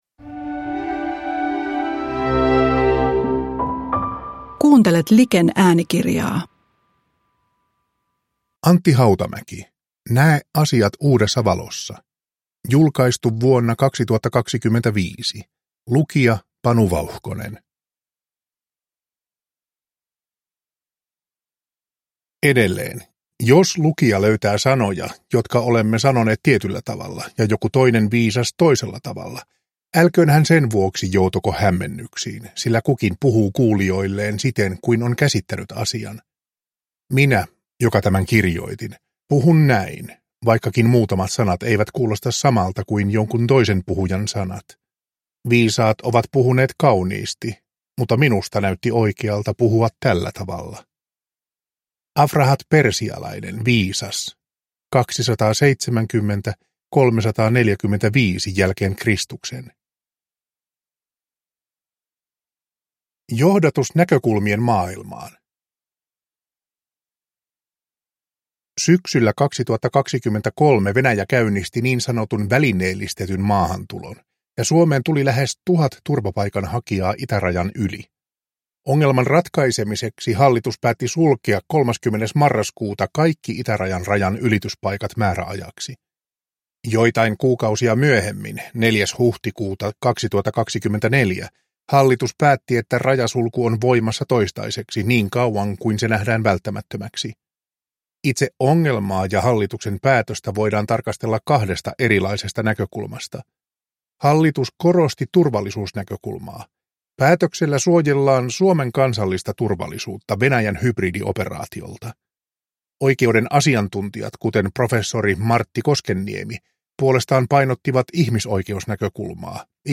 Näe asiat uudessa valossa – Ljudbok